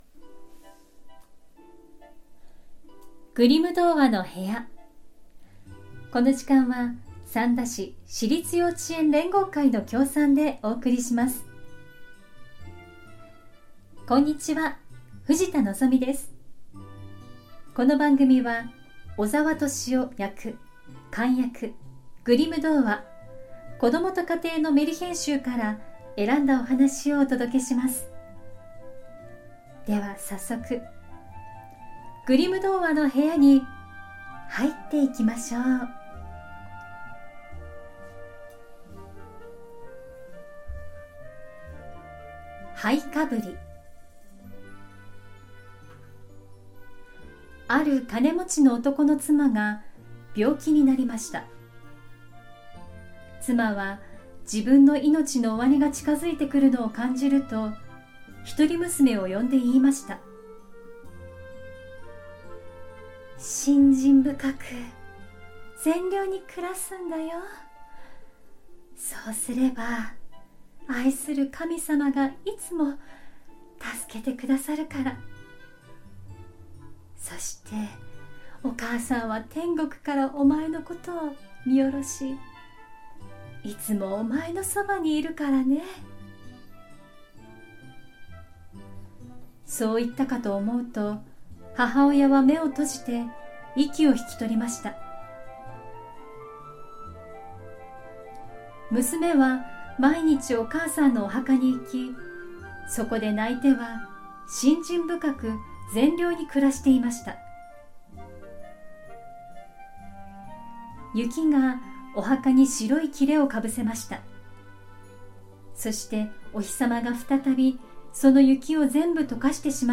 グリム兄弟によって集められたメルヒェン（昔話）を、翻訳そのままに読み聞かせします📖 今回お届けするのは『灰かぶり』。 「シンデレラ」としてよく知られるお話です✨ 魔法のおばあさんや動物たちに助けられてお城の舞踏会に参加し、ガラスの靴を残して…というのは映画や絵本で定番のストーリーですが、実際のところは異なる展開をするようです📖 11月配信では前半をお届けしましょう♪昔話の本当のストーリーを、お子さんも大人の方もどうぞお楽しみください🌷